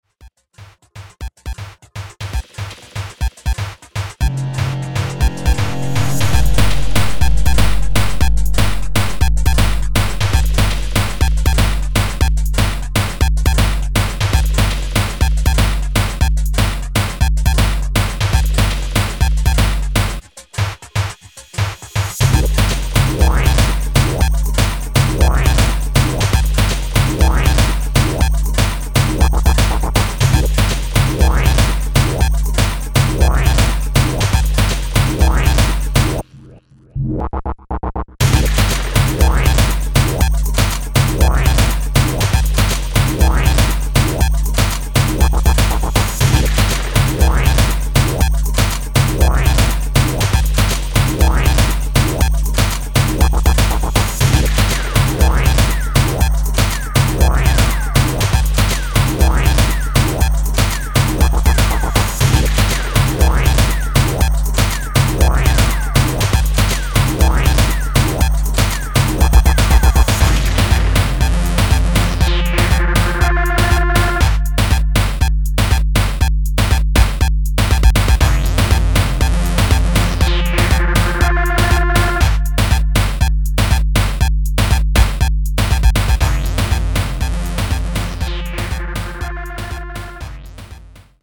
electro sound